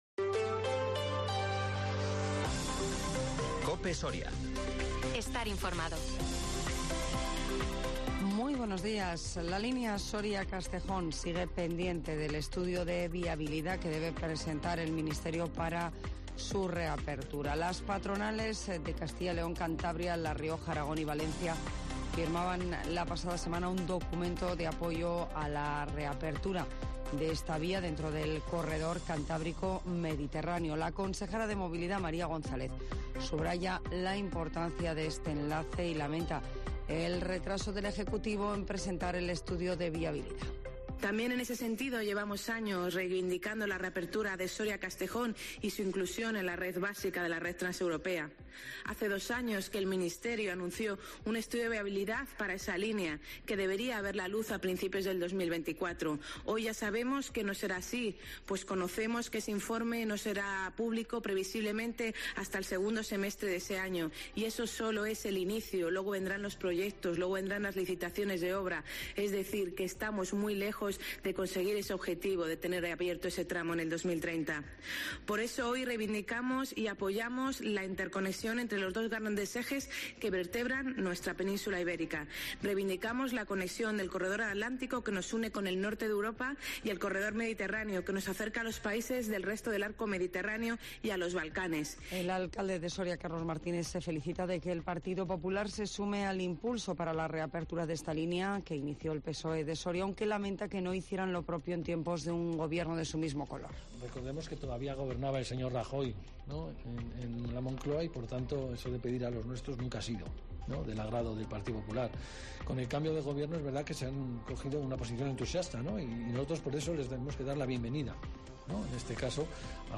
Las noticias en COPE Soria